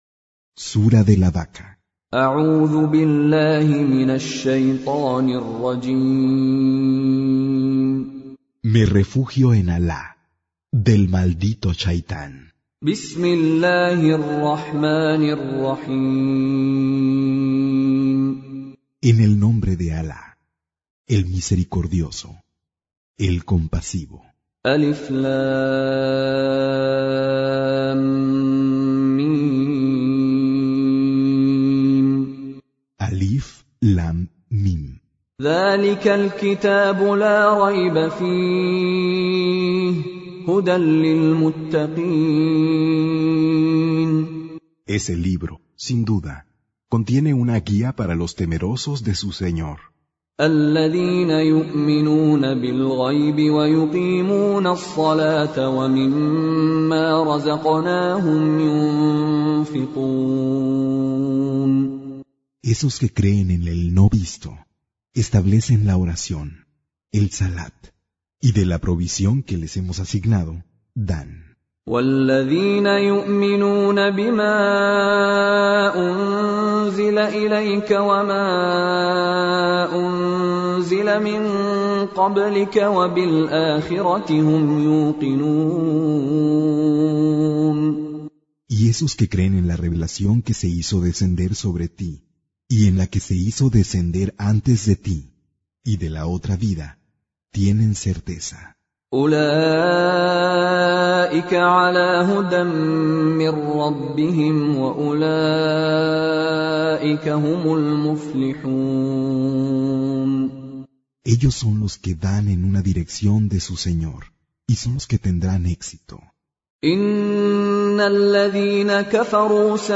Traducción al español del Sagrado Corán - Con Reciter Mishary Alafasi
Surah Repeating تكرار السورة Download Surah حمّل السورة Reciting Mutarjamah Translation Audio for 2. Surah Al-Baqarah سورة البقرة N.B *Surah Includes Al-Basmalah Reciters Sequents تتابع التلاوات Reciters Repeats تكرار التلاوات